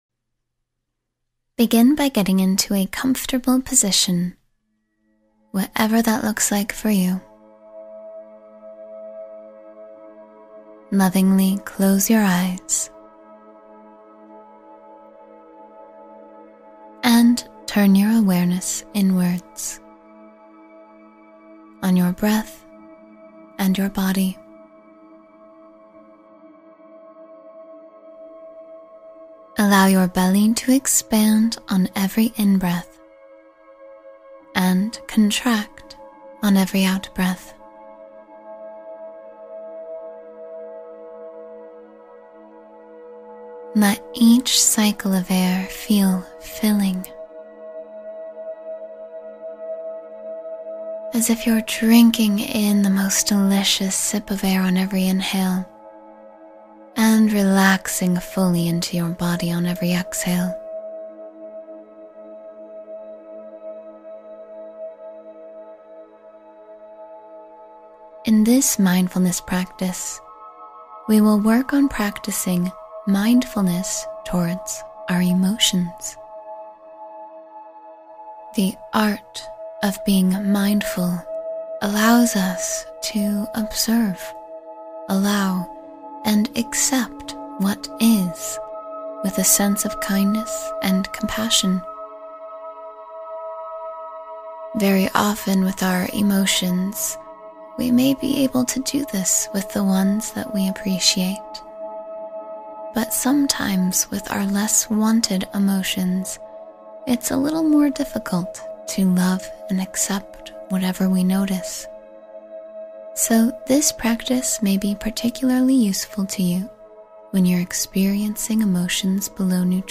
Mindful Awareness of Your Emotions — Guided Meditation for Emotional Balance